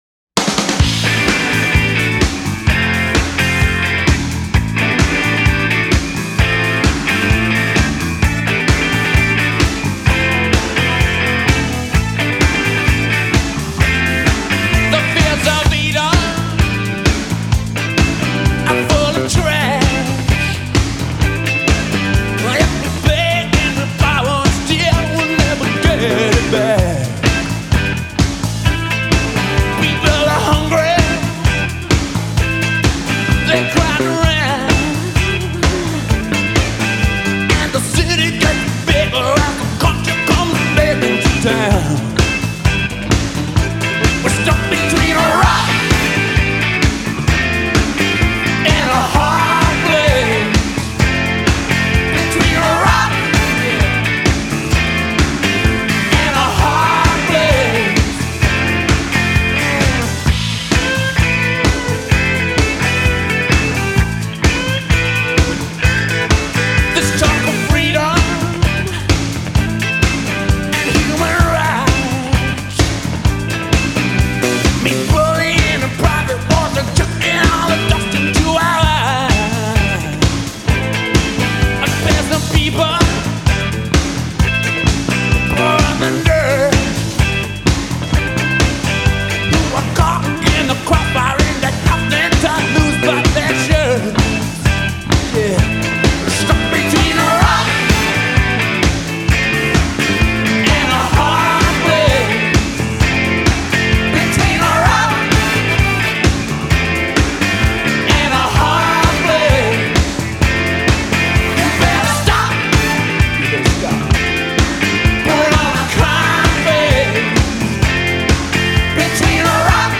ژانر: راک